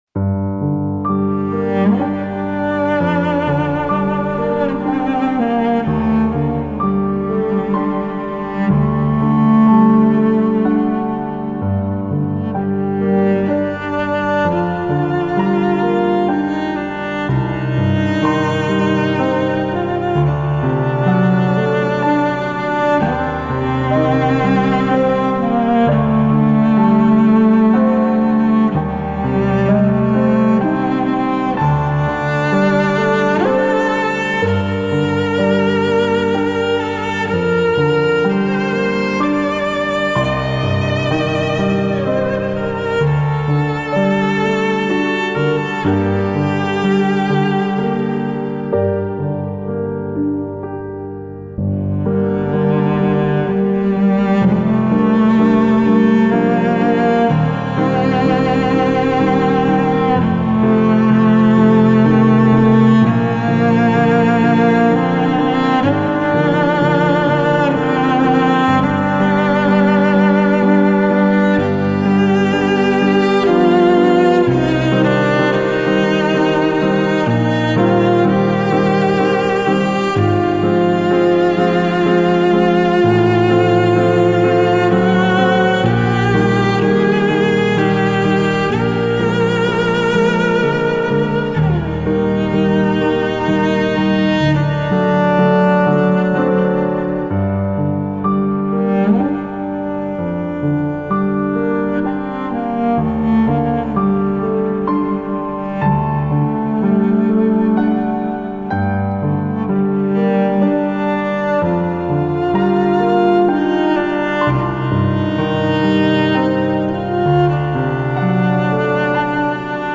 Better Tomorrow (ورژن ترکیبی پیانو+ویولن) بیکلام